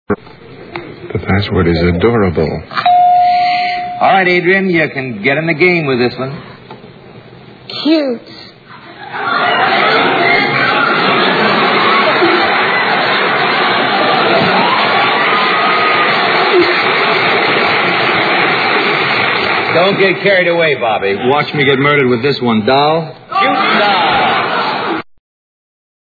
On January 6, 1963 Bobby Darin was a guest contestant on the night-time version of the popular CBS game show Password.